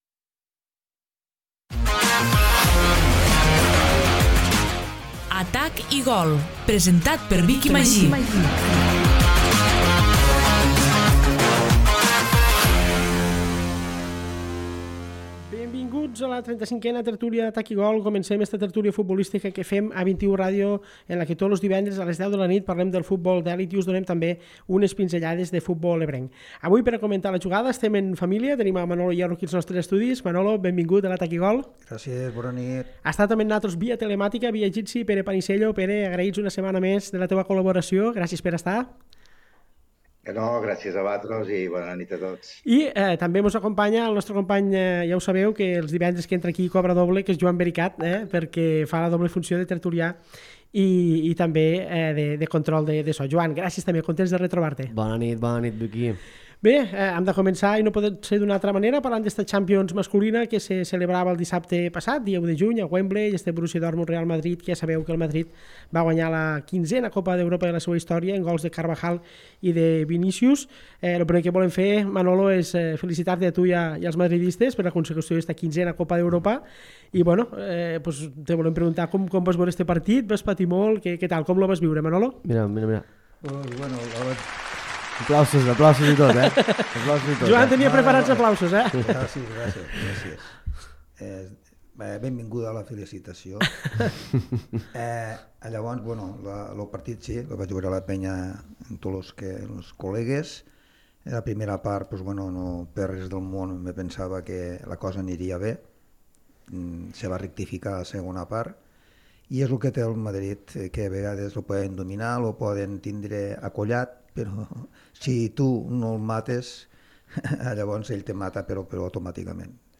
Avui farem un nou episodi d’Atac i gol, la tertúlia futbolística de 21 Ràdio en la que tots els divendres, a les 22.00, parlem del futbol d’elit i també us donem unes pinzellades de futbol ebrenc.